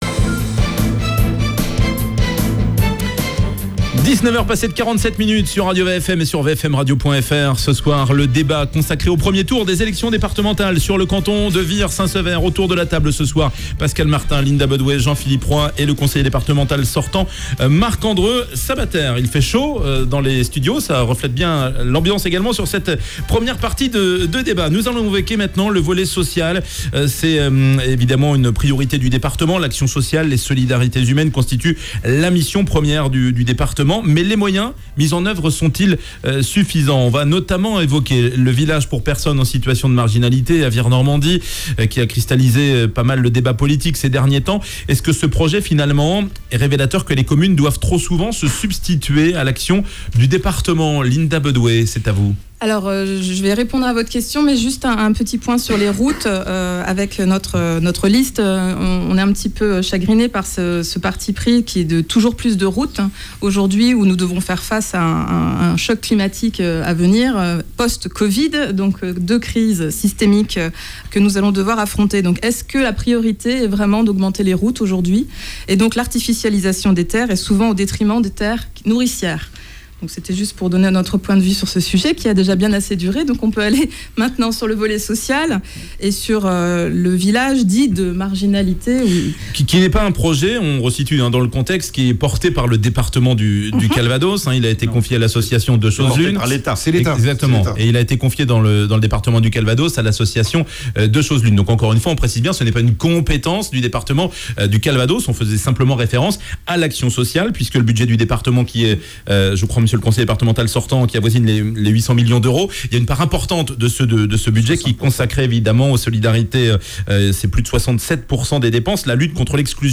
Débat Vire Normandie/Noue de Sienne